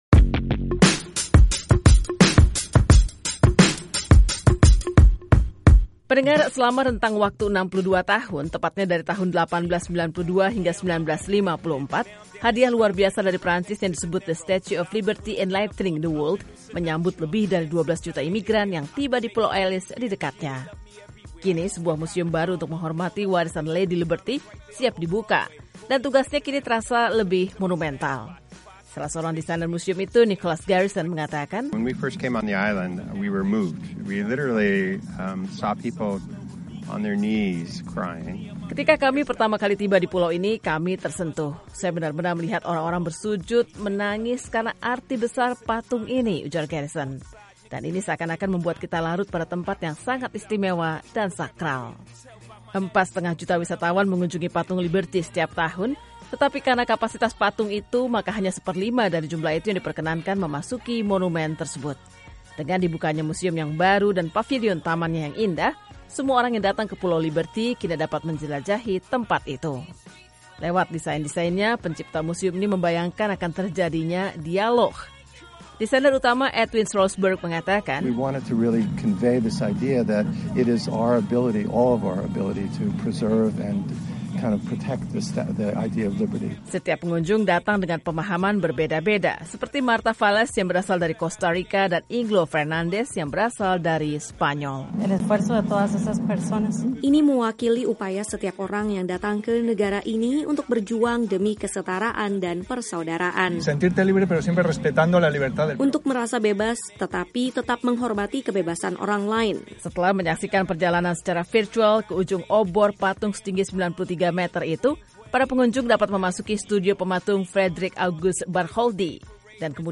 berikut laporannya.